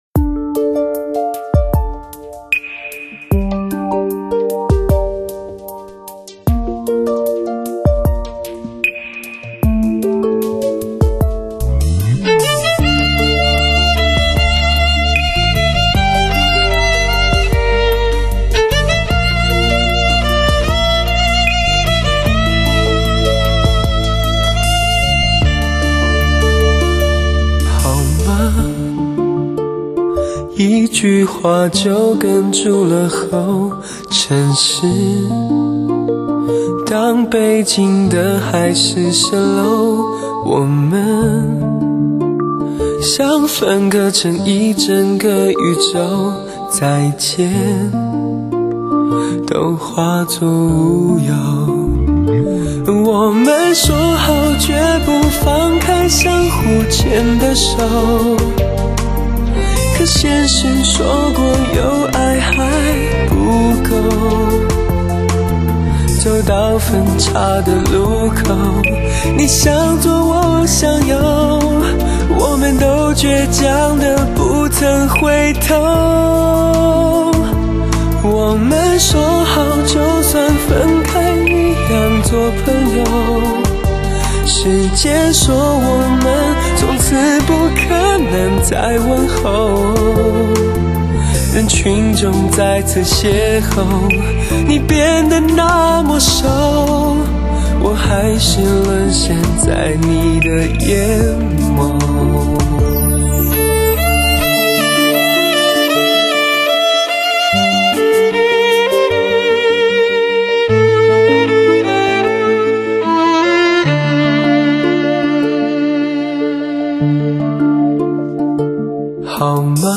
采用最先进的数码音频工作站Pyramix灌录处理，母带直刻。